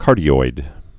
(kärdē-oid)